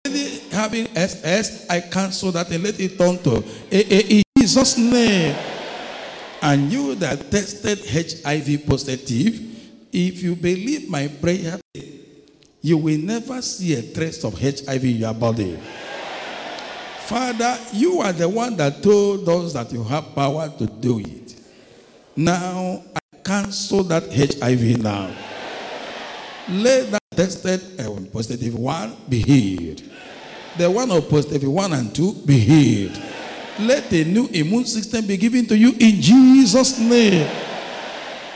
prayer20.mp3